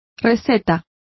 Complete with pronunciation of the translation of recipe.